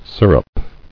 [sir·up]